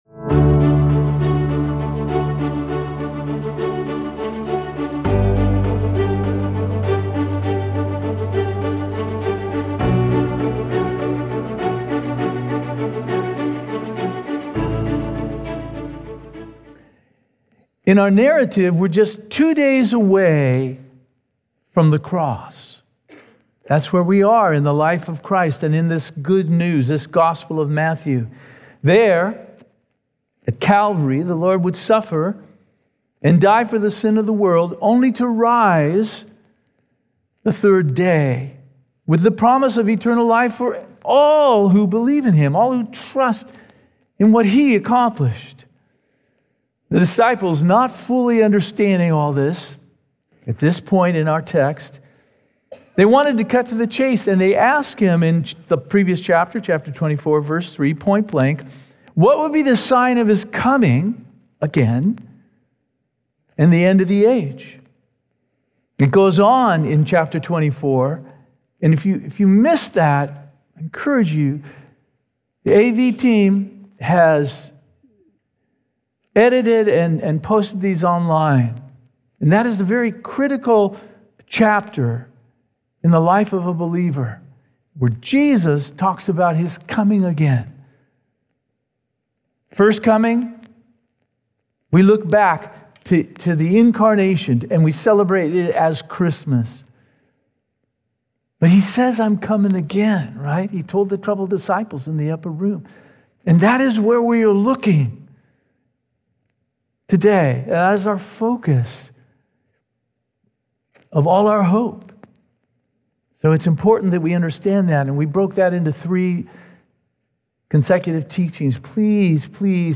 This is an audio podcast produced by Calvary Chapel Eastside in Bellevue, WA, featuring live recordings of weekly worship services.